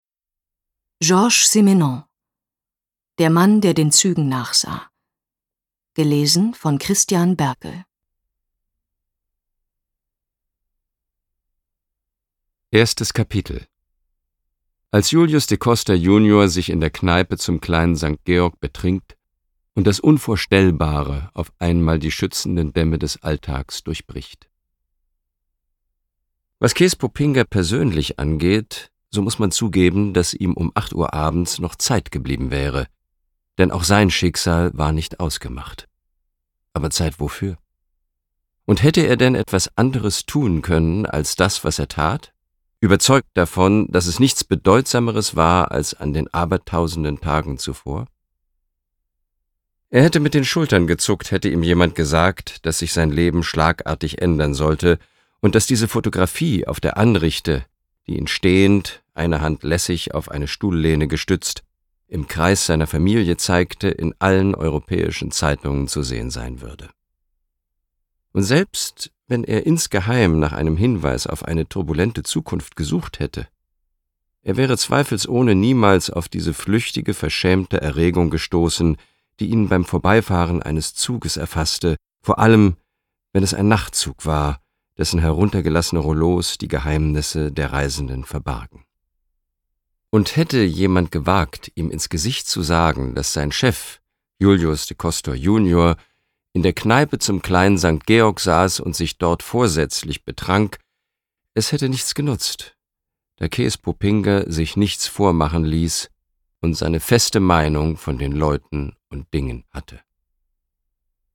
Ungekürzte Lesung mit Christian Berkel (5 CDs)
Christian Berkel (Sprecher)